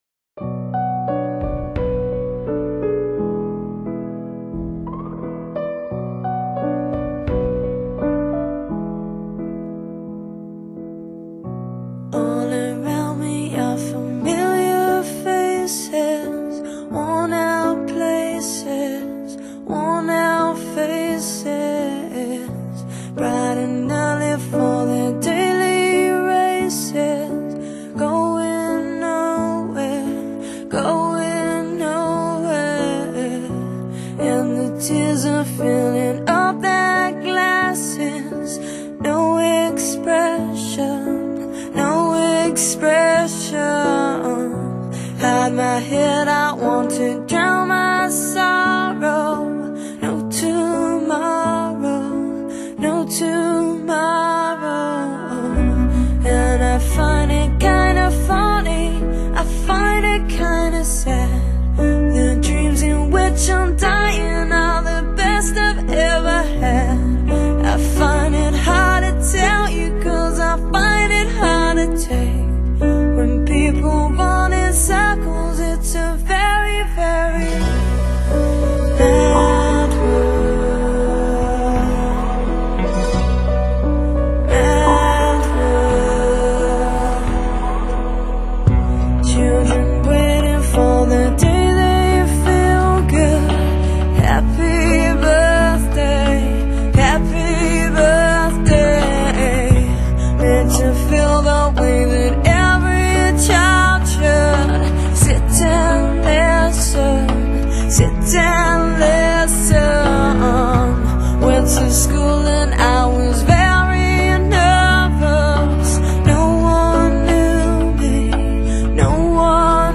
由忧郁到悲伤，由悲伤到绝望，由绝望到无助，对这个迷失世界的不齿用如此哀艳凄宛的音符表达出来，似乎整个天空都变成深灰色了。